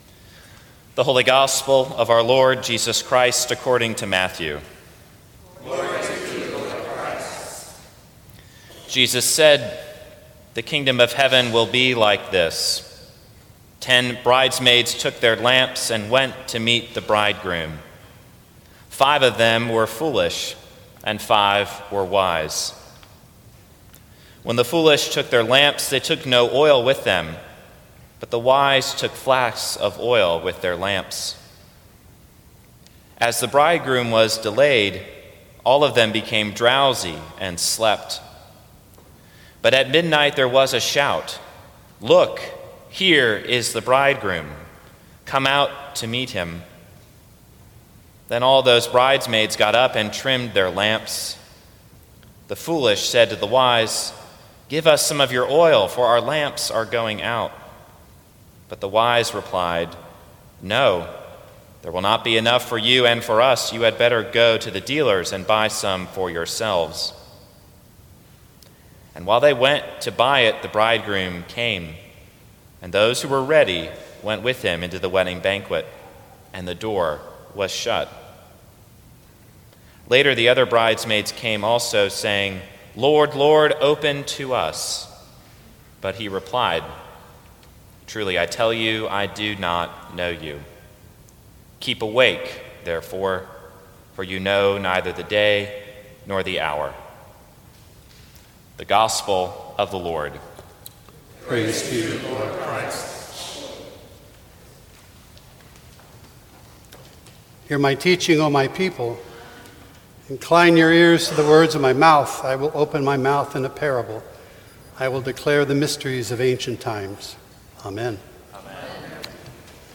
Sermons from St. Cross Episcopal Church Light in the World Nov 13 2017 | 00:13:08 1x 00:00 / 00:13:08 Subscribe Share Apple Podcasts Spotify Overcast RSS Feed Share Link Embed